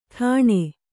♪ ṭhāṇe